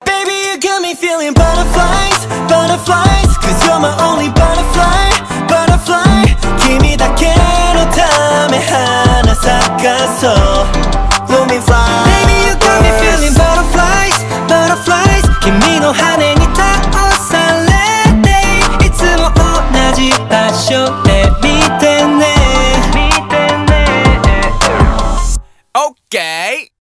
поп
k-pop